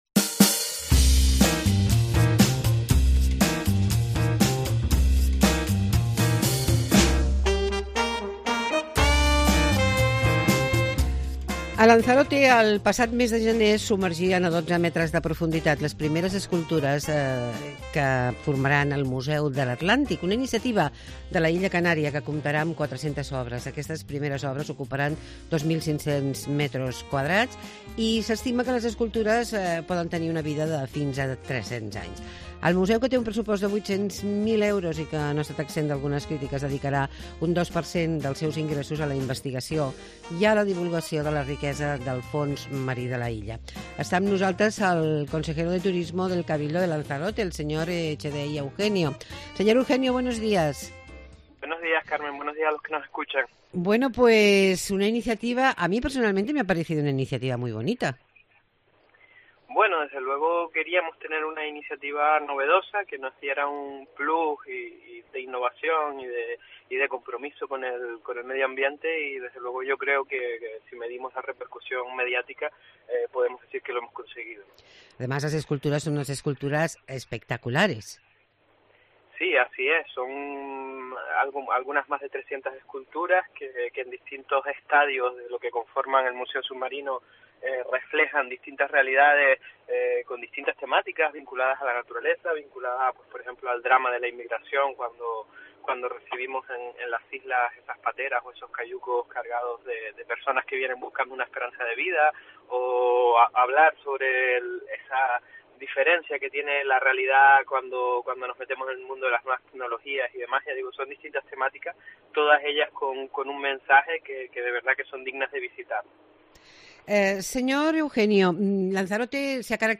Entrevista a Echedey Eugenio, consejero de Turismo de Lanzarote. Objetivo: conocer el Museo submarino